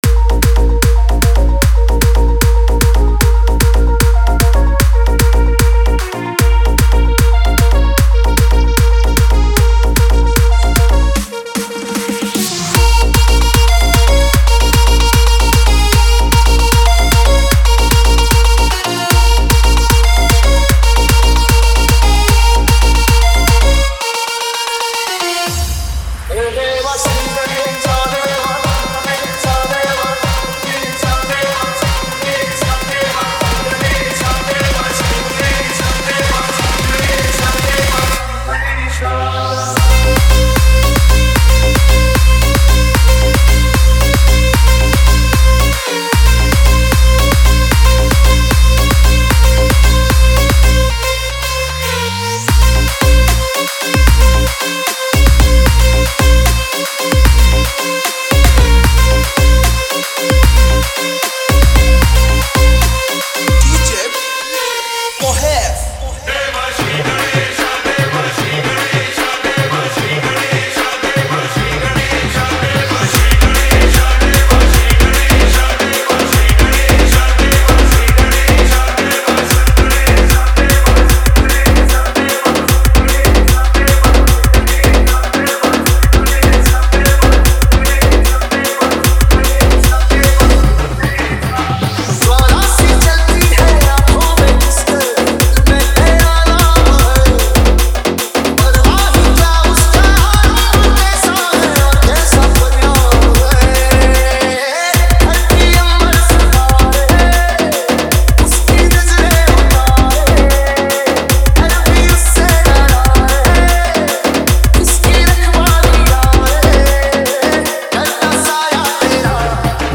Ganesh Puja Special Dj 2022 Songs Download